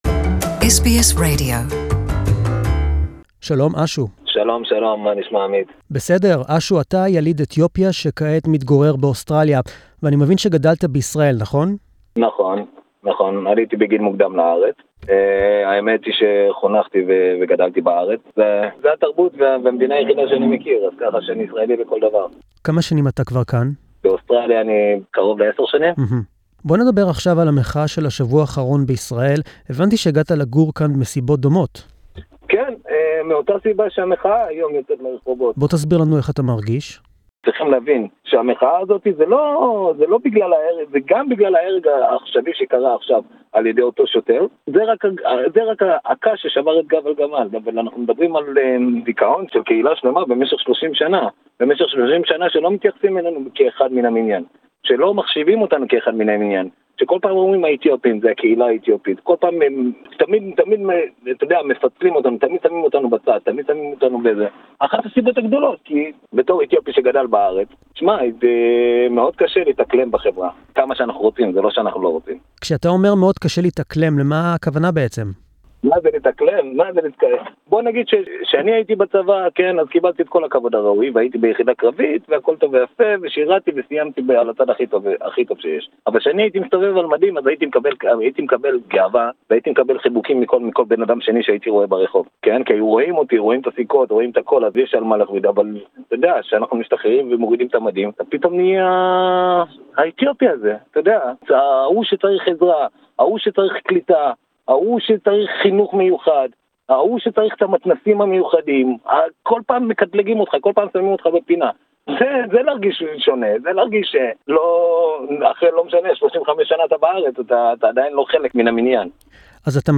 The death of an Ethiopian-Jewish teen at the hands of an off-duty police officer has sparked protests across Israel. Israeli police allowed the protests but had to step in when they turned into violent riots. An interview